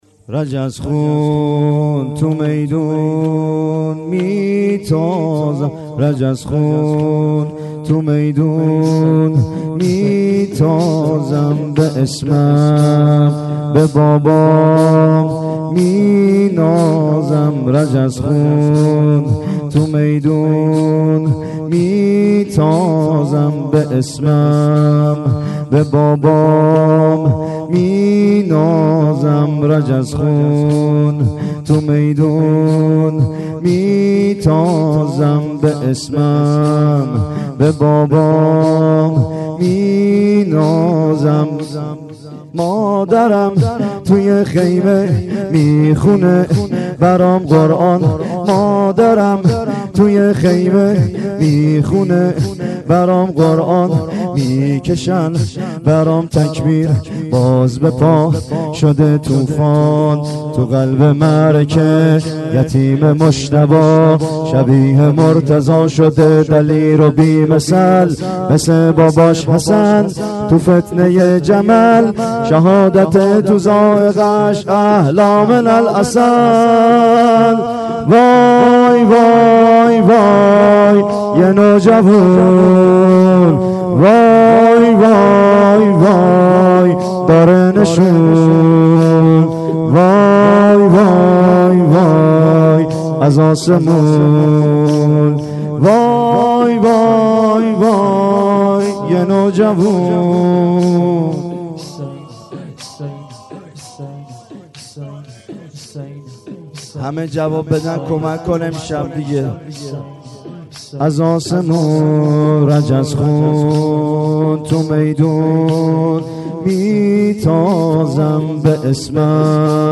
گلچین زمینه های محرم 93
زمینه شب ششم : رجز خون تو میدون می تازم